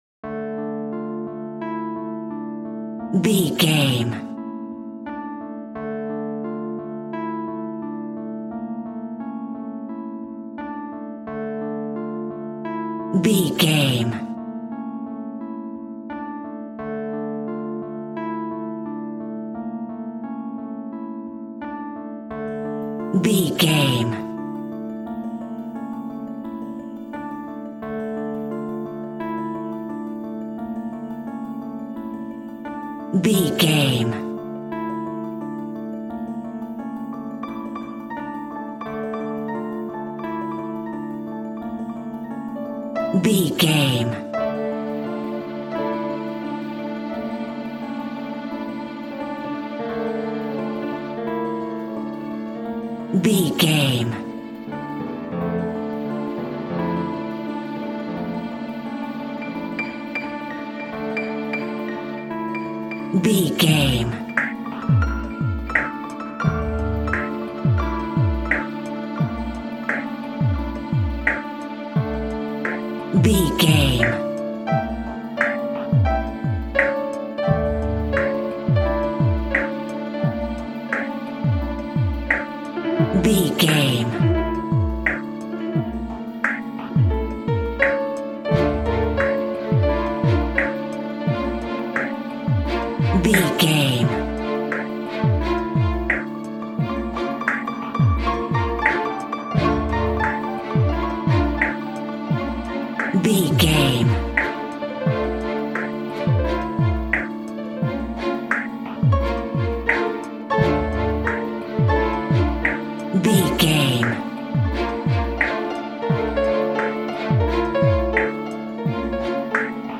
Aeolian/Minor
D
tension
ominous
dark
haunting
eerie
synthesiser
piano
strings
drums
ambience
pads